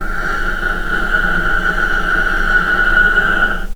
healing-soundscapes/Sound Banks/HSS_OP_Pack/Strings/cello/ord/vc-F#6-pp.AIF at 61d9fc336c23f962a4879a825ef13e8dd23a4d25
vc-F#6-pp.AIF